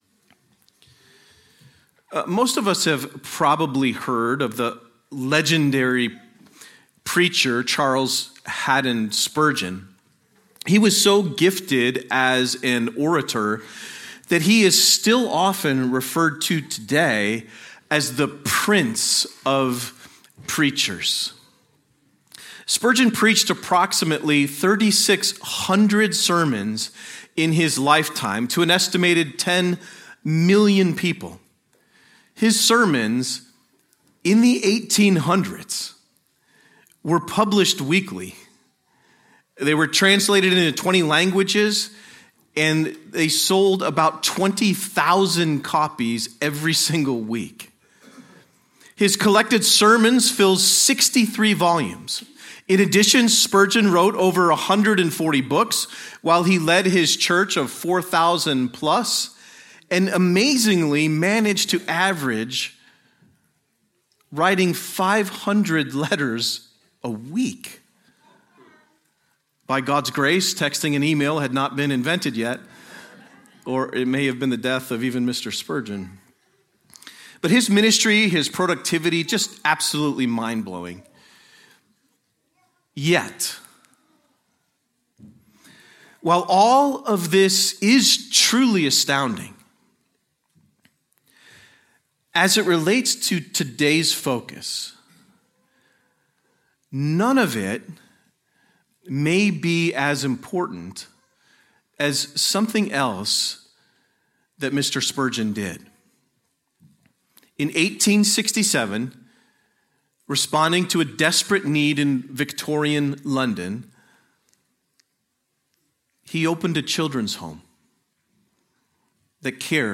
A sermon on James 1:26-27